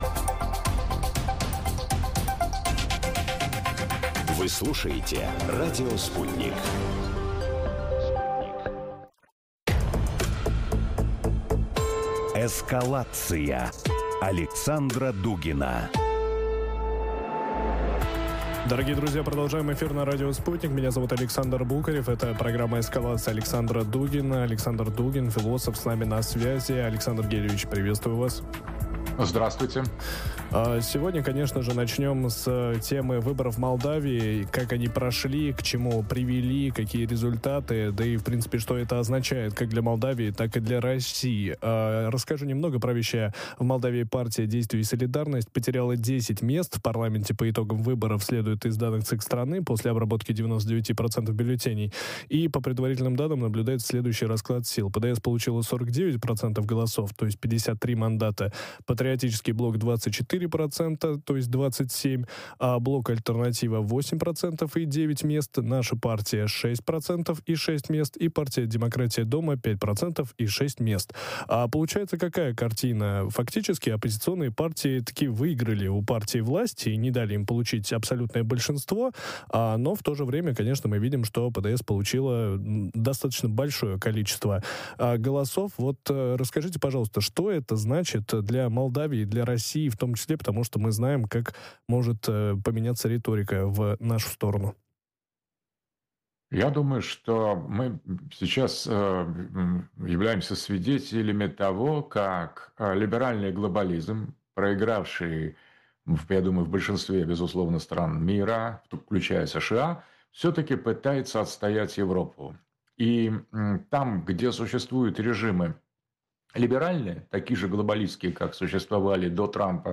Ответы ищем в эфире радио Sputnik вместе с философом Александром Дугиным.